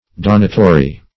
donatory - definition of donatory - synonyms, pronunciation, spelling from Free Dictionary